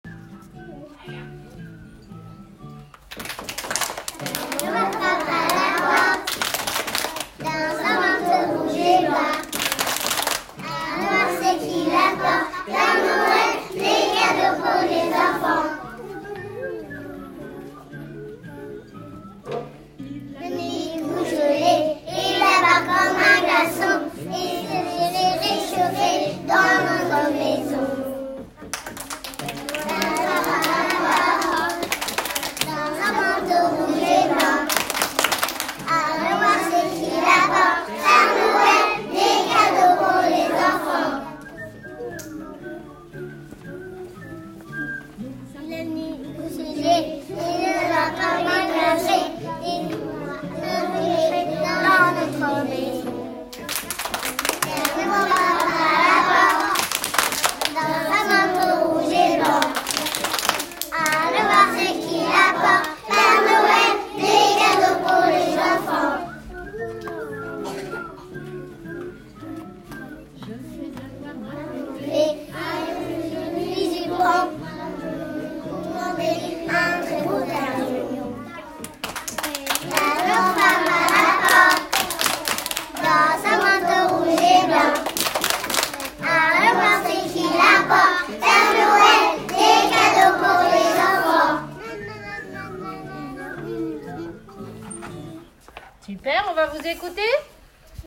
Ecole Maternelle Publique Jean de La Fontaine de Gagny Exposition et chants de Noël